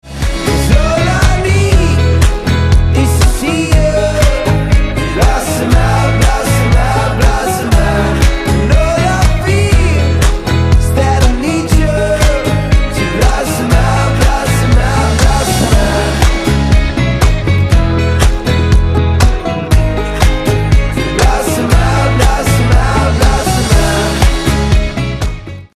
• Качество: 128, Stereo
мужской вокал
dance
vocal